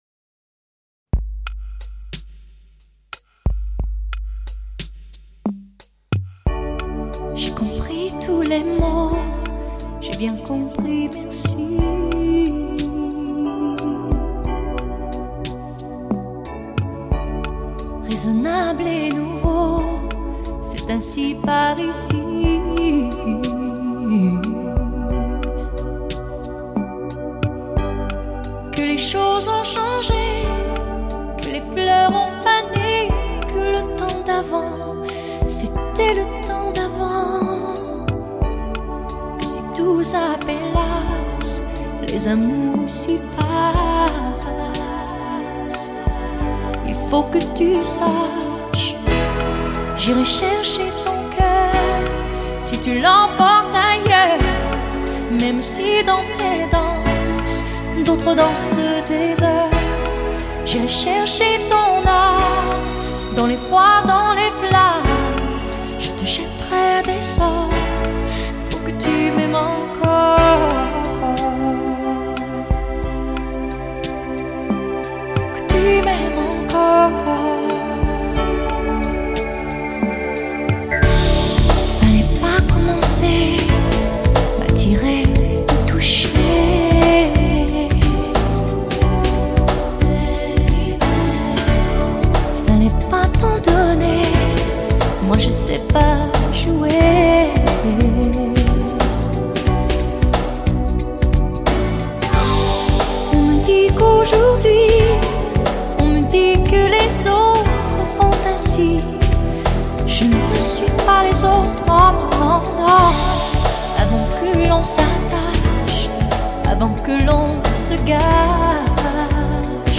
曲风在流行、民谣、爵士及70年代的灵魂乐间游走交融，交织成一张充满浓烈与激昂情意的作品